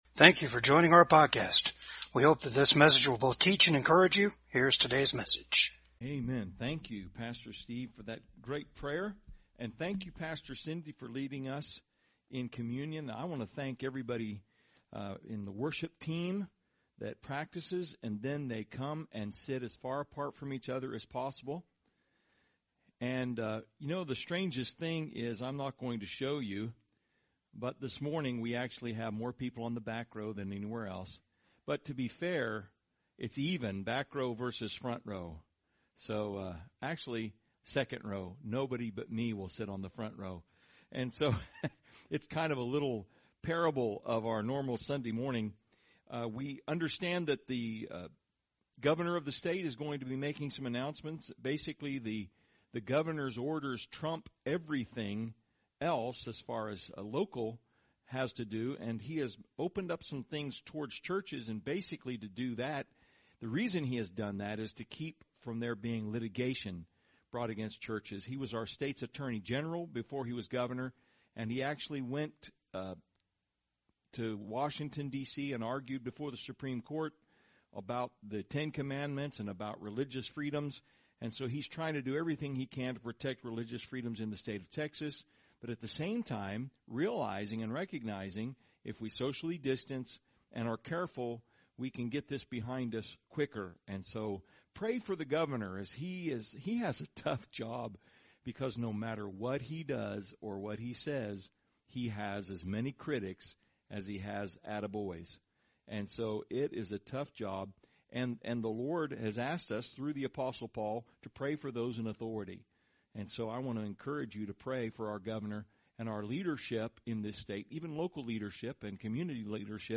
Service Type: VCAG SUNDAY SERVICE